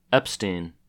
En-us-Epstein.ogg.mp3